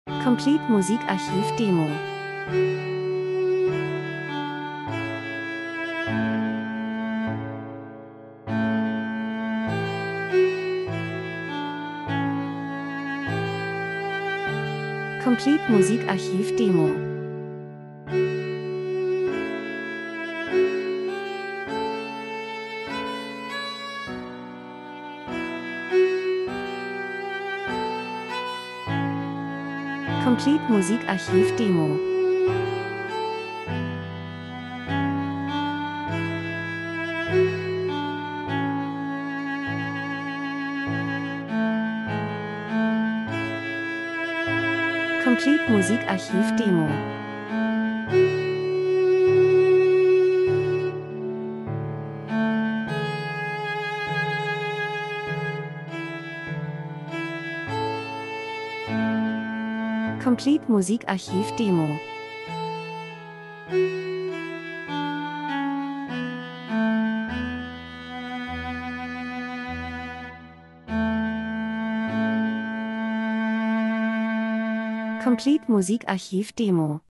Viola und Piano
in der warmem Stube der Kamin knistert Wohlgefühl   01:16